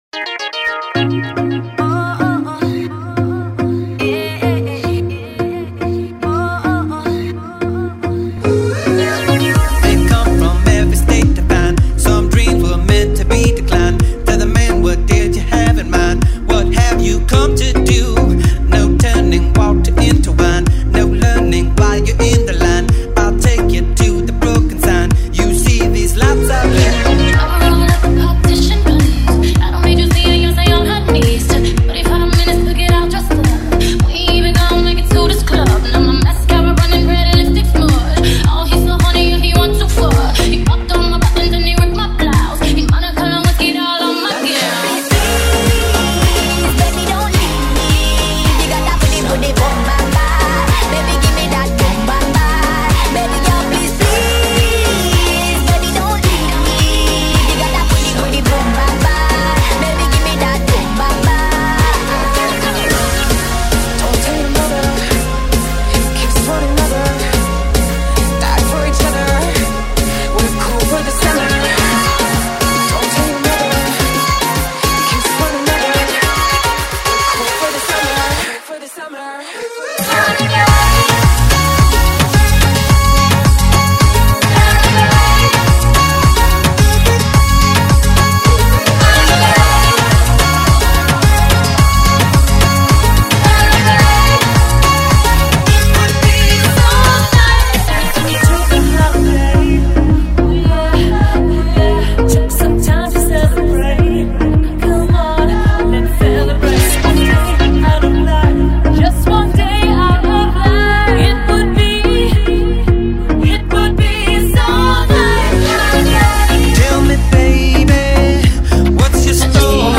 Gran mashup